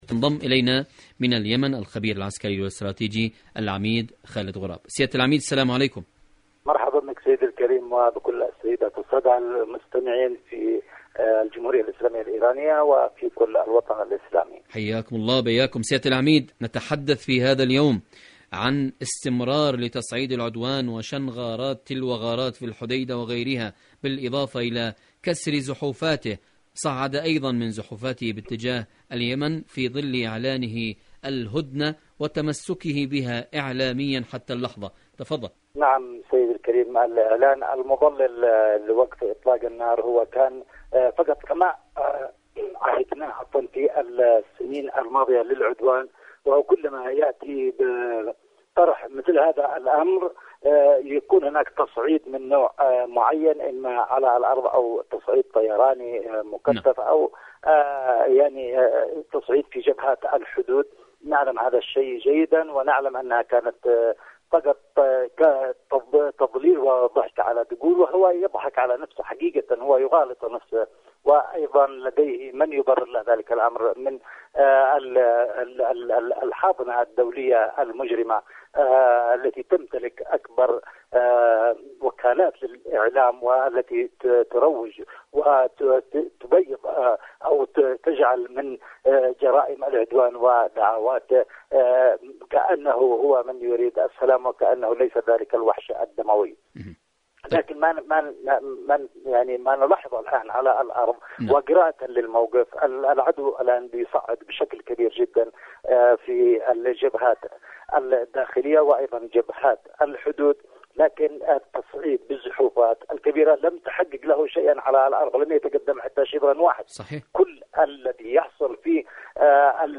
إذاعة طهران-اليمن التصدي والتحدي: مقابلة إذاعية